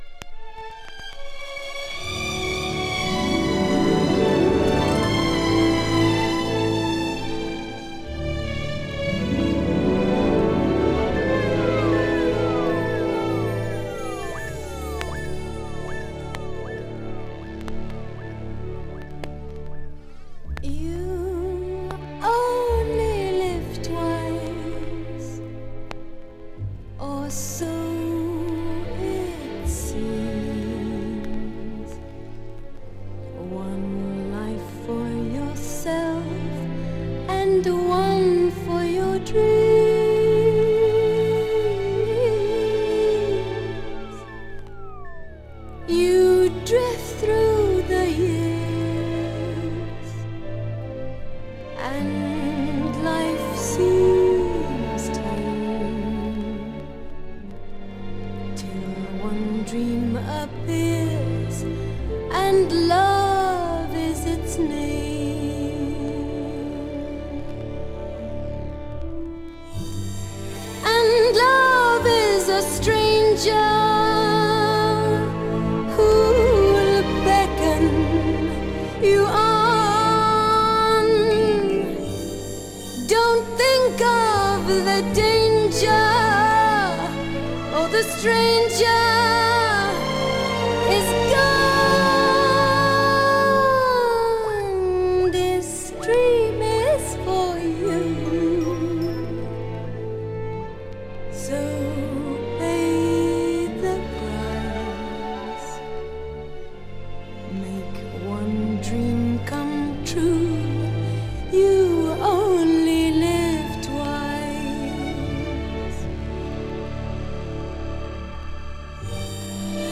壮大でサントラのような世界観を演出するノンビート・ナンバー